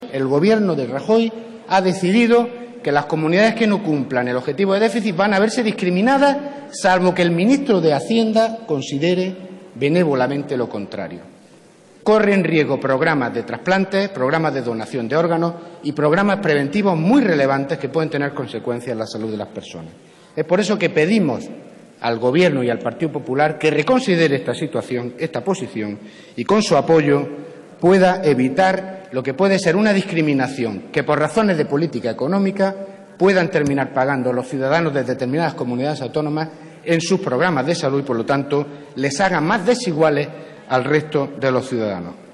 José Martínez Olmos defiende una moción por la sanidad pública, gratuita y universal. 16/04/2013